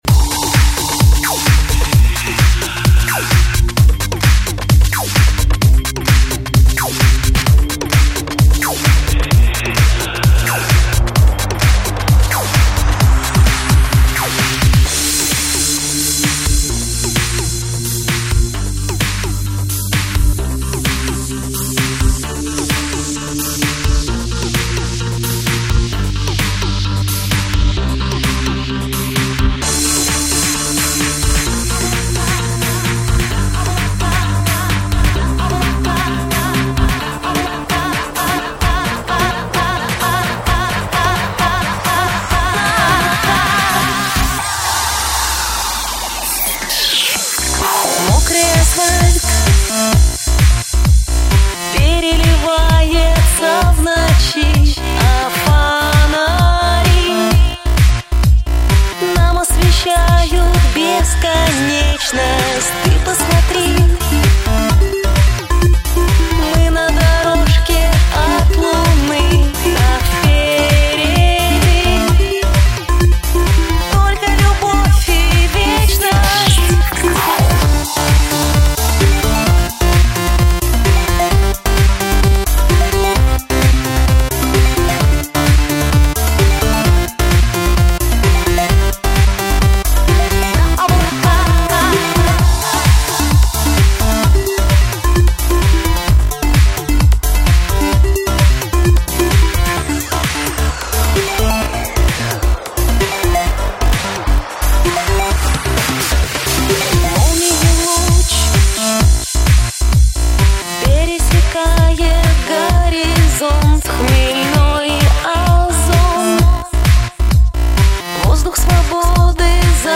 *** стиль electro house ***